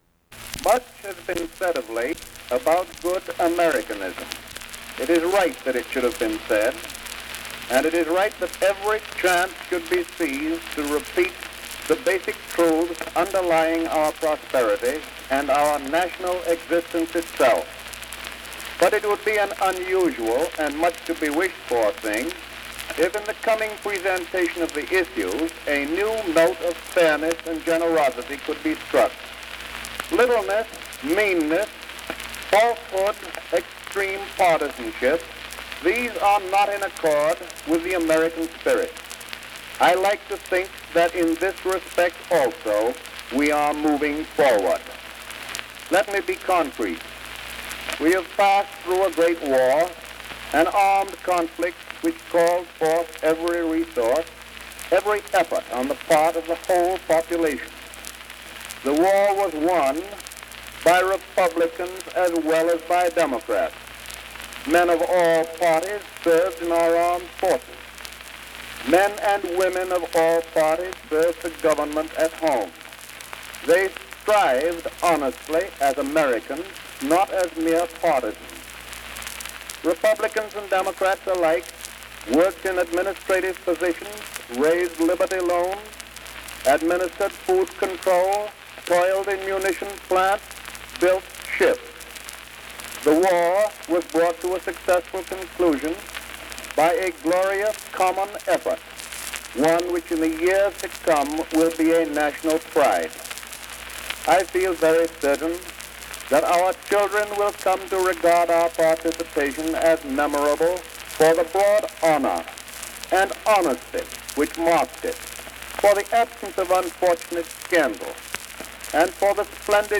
Recorded by Nation's Forum, 1920.